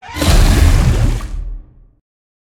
Sfx_creature_bruteshark_death_01.ogg